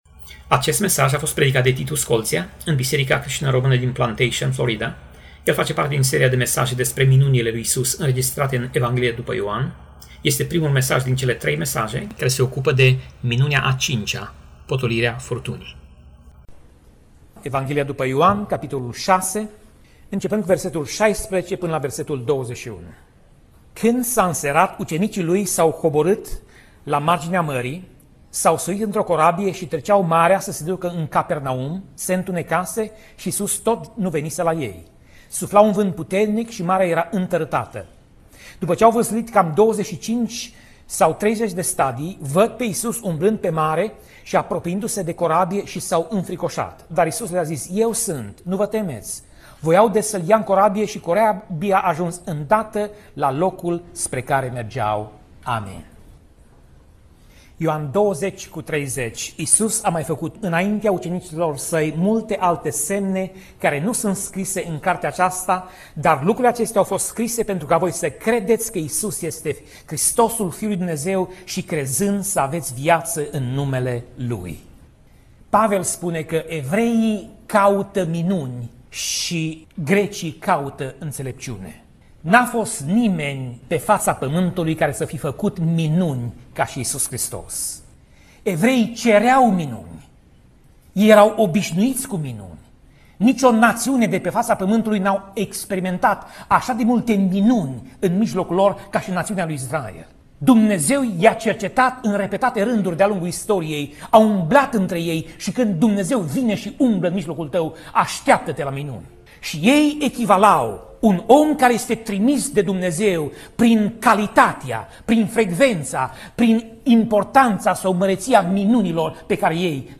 Pasaj Biblie: Ioan 6:16 - Ioan 6:21 Tip Mesaj: Predica